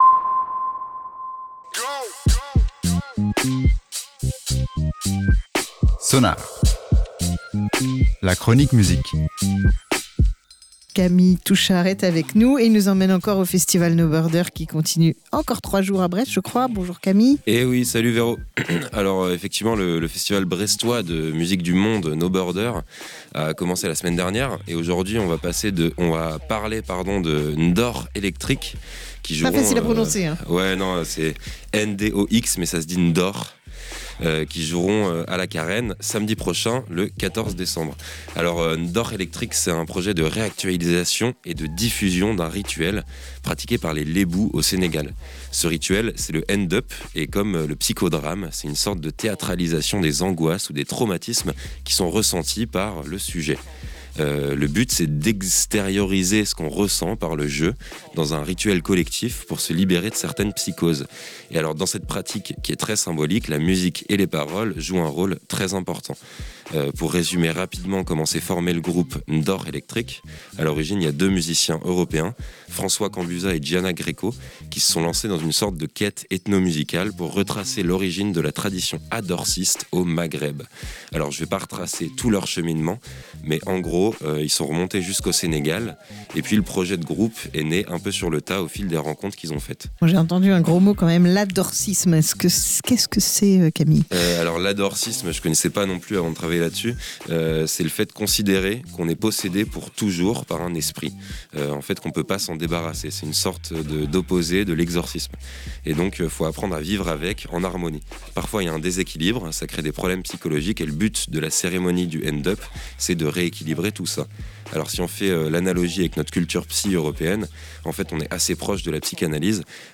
Les voix se mèlent aux guitares saturées et aux sabar hypnotiques (percussions wolof) pour nous transporter dans une transe collective.